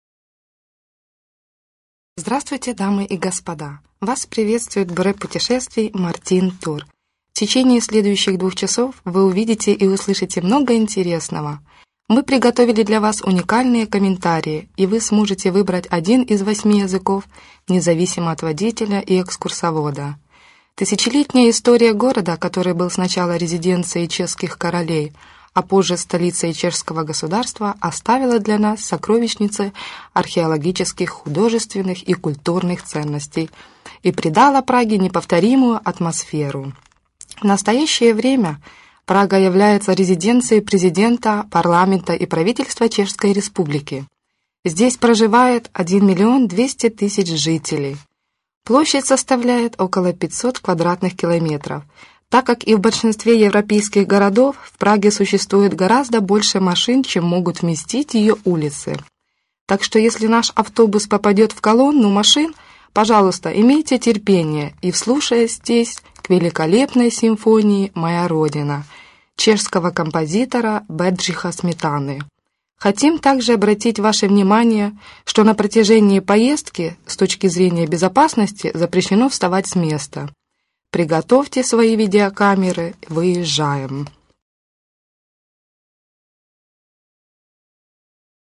commentary-ru.mp3